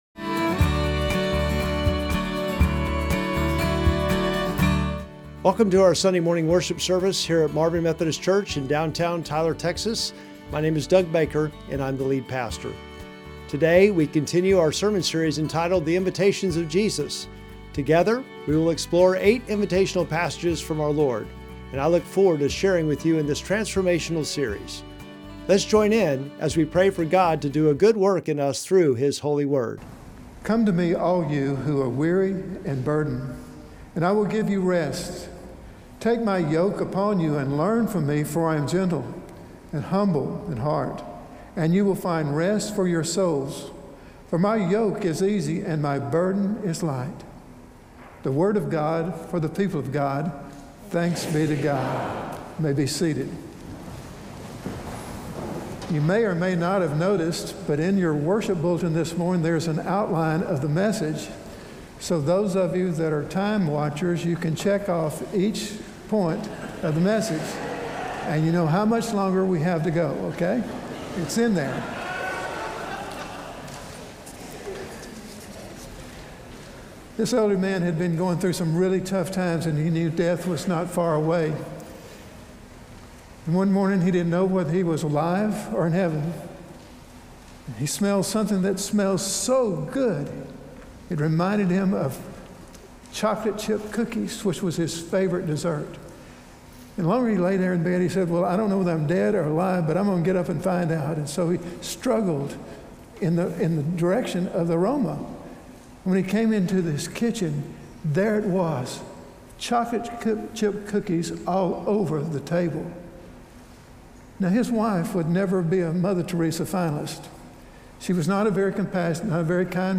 Sermon text: Matthew 11:28-30